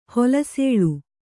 ♪ holasēḷu